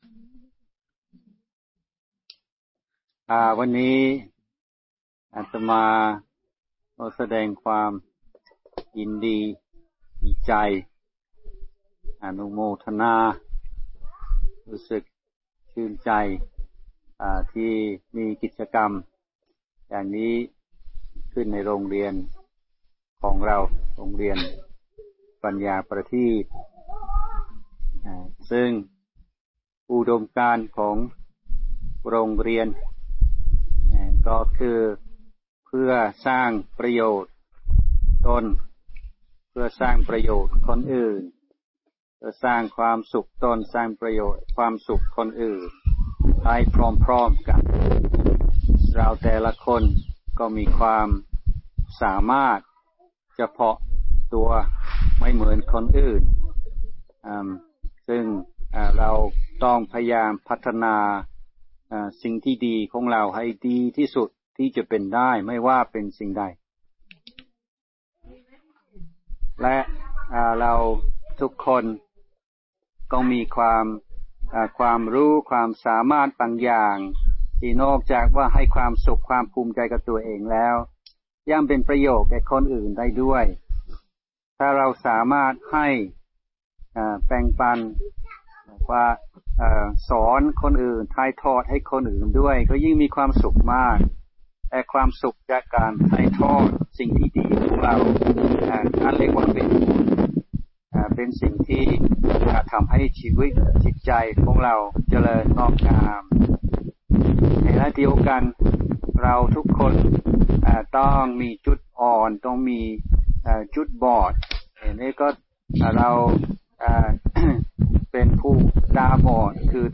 ฟังธรรมะ Podcasts กับ พระธรรมพัชรญาณมุนี (ฌอน ชยสาโร)